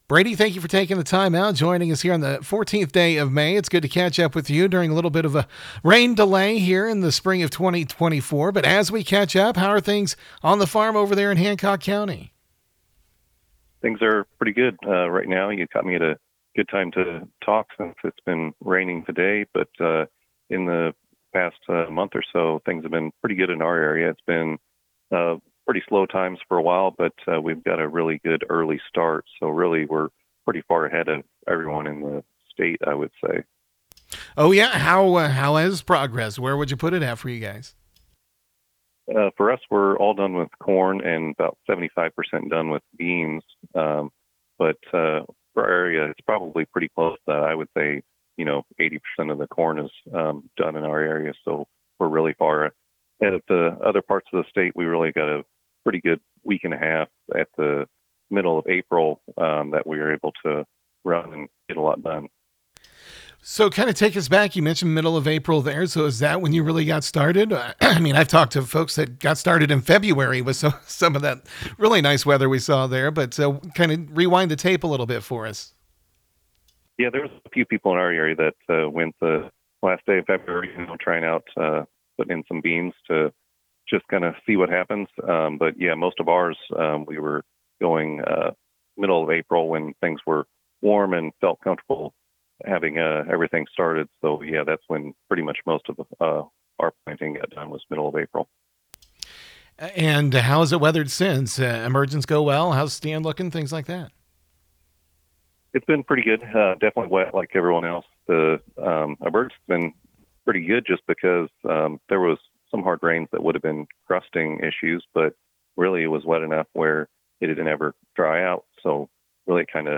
Western Illinois farmer satisfied with planting progress so far